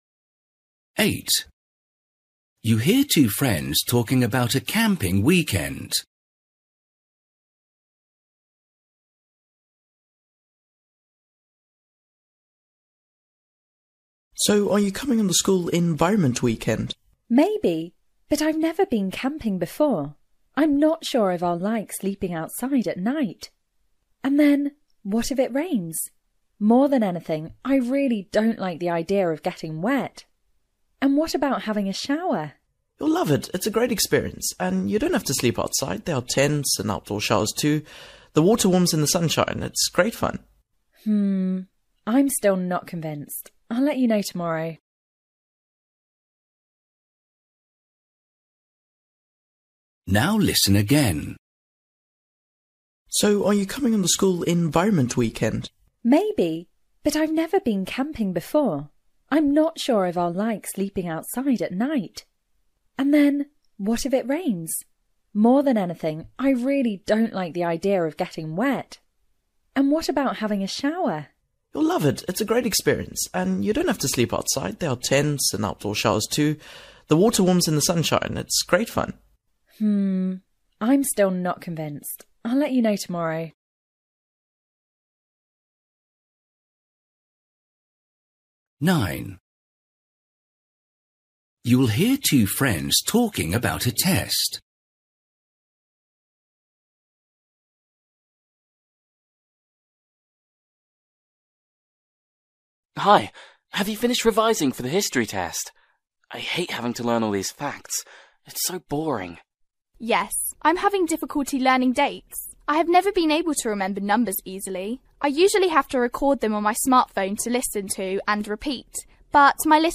Listening: everyday short conversations
8   You hear two friends talking about a camping weekend. What is the girl’s main worry?
10   You will hear a girl telling a friend about her windsurfing lesson. How did she feel about it?
11   You will hear two friends talking about a film they have seen. They agree that it has